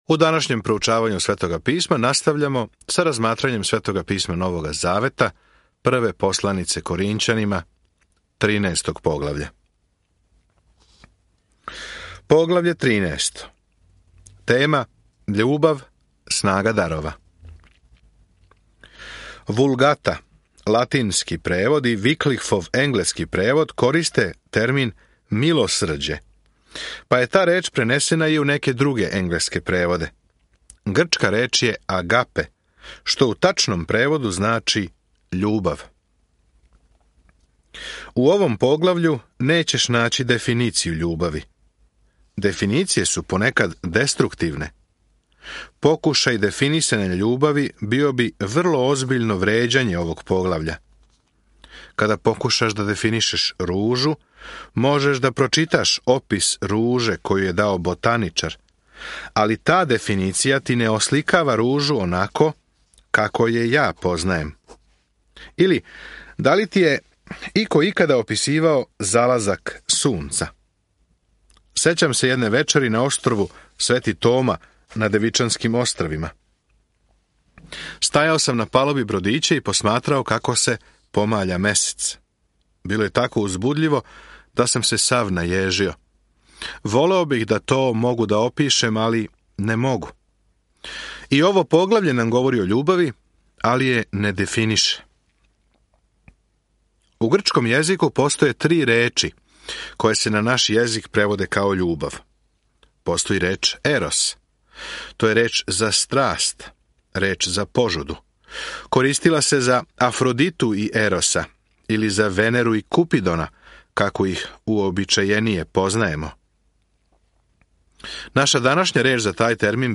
Коринћанима док слушате аудио студију и читате одабране стихове из Божје речи.